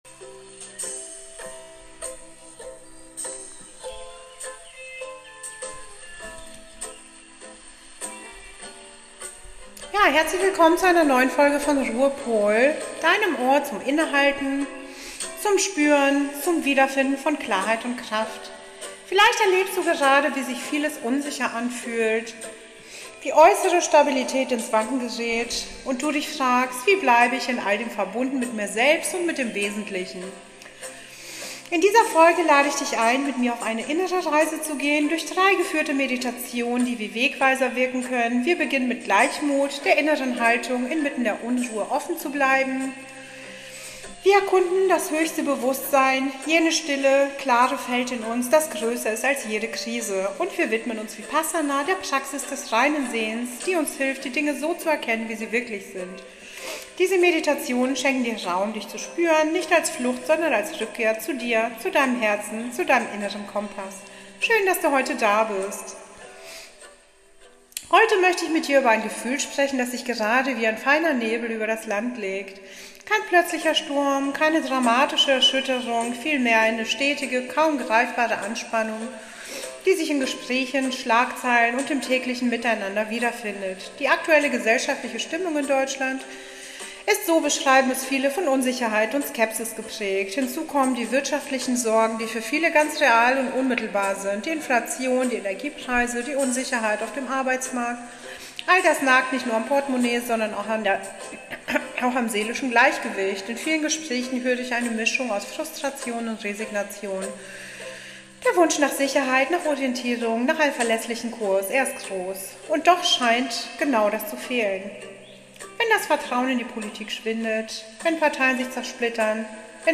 In dieser besonderen Episode nehme ich dich mit auf eine Reise durch drei geführte Meditationen – zu Gleichmut, deinem höchsten Bewusstsein und der klaren Sicht des Vipassana.